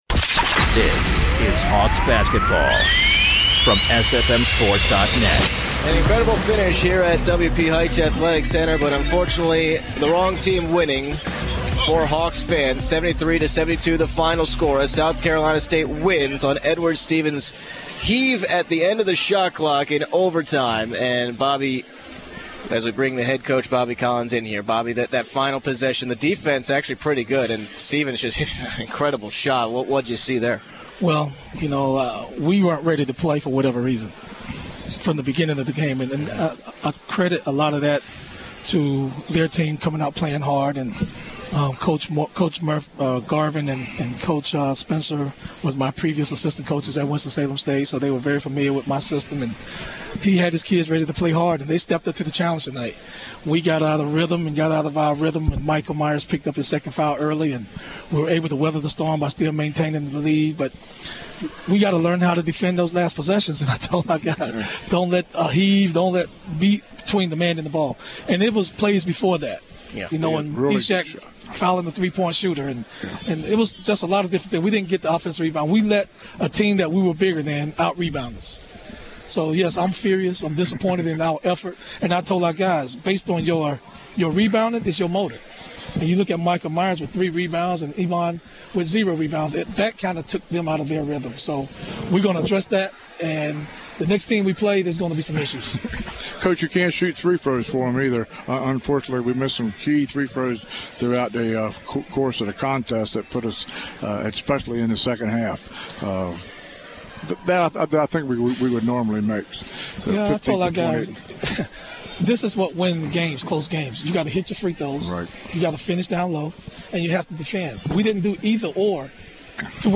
1/19/15: UMES Men Basketball Post Game Show